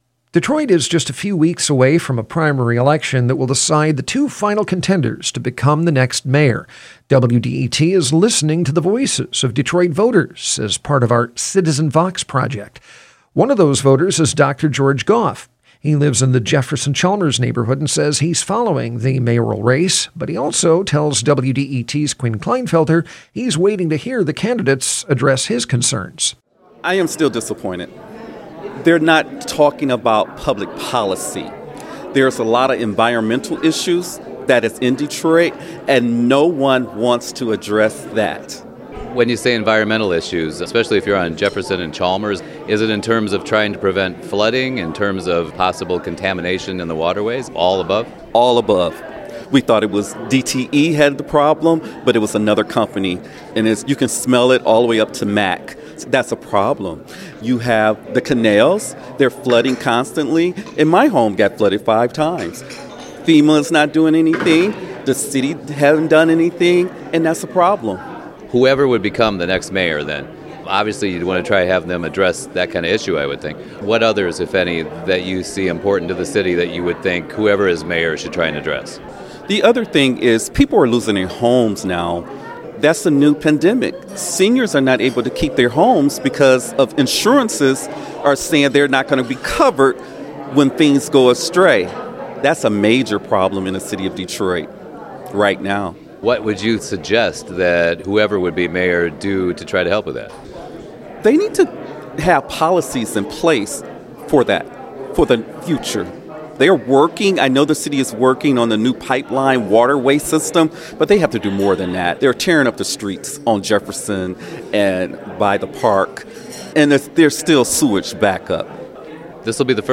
The following interview has been edited for clarity and length.